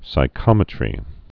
(sī-kŏmĭ-trē)